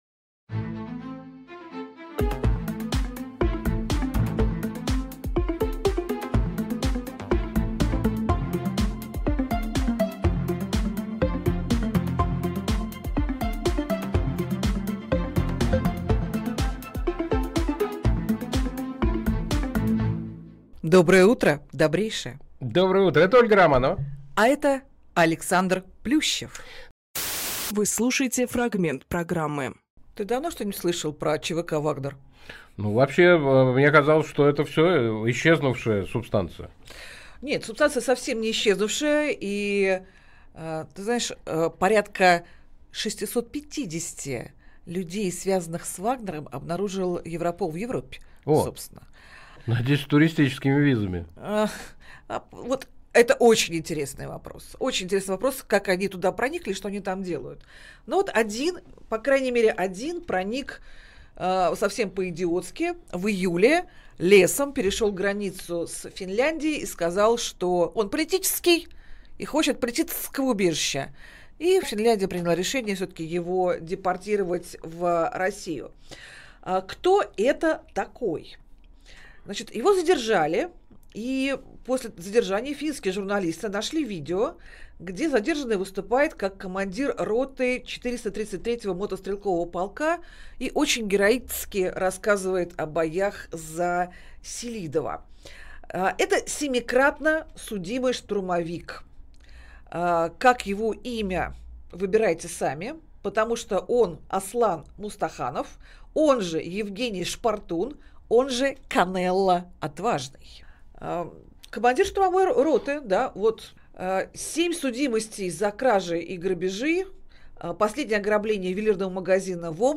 Фрагмент эфира от 17.11.25